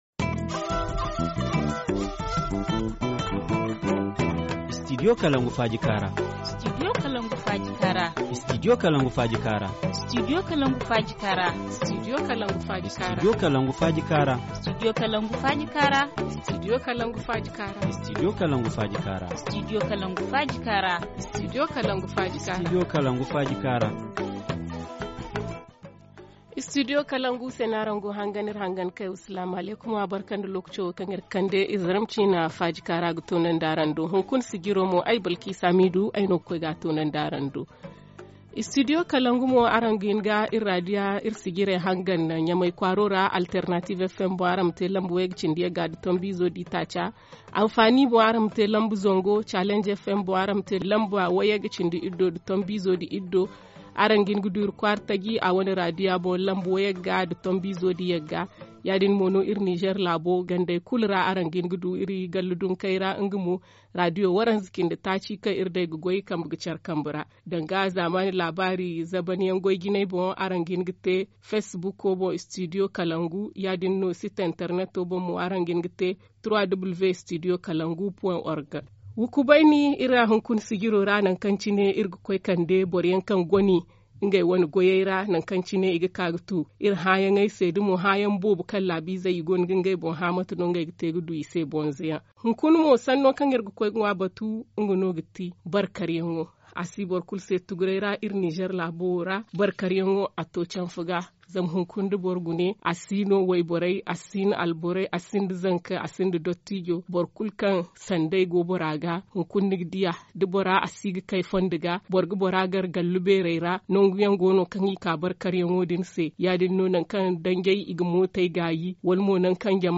Forum Zarma 11/02/2018 : Le fléau de la mendicité au Niger - Studio Kalangou - Au rythme du Niger